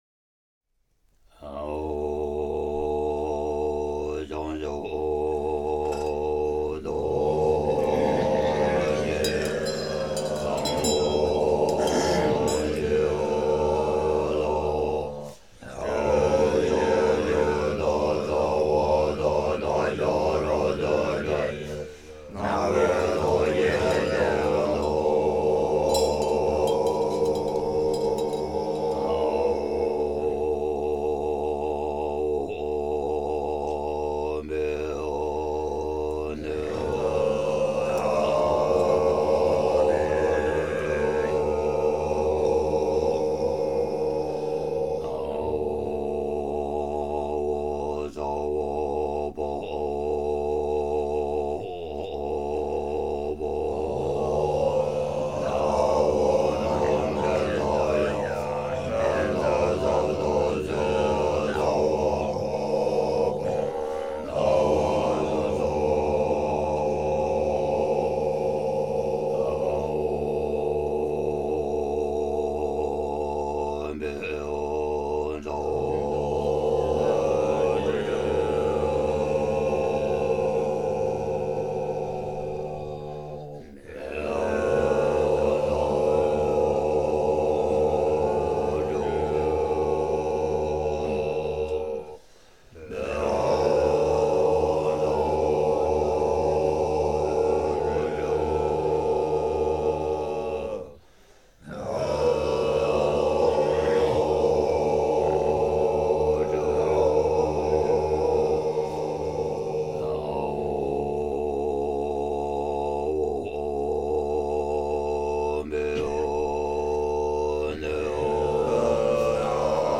Музыка тибетских монахов!
Тибетская музыка